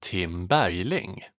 ^ Swedish pronunciation: [ˈtɪmː ˈbæ̂rjlɪŋ]
Sv-Tim_Bergling.ogg.mp3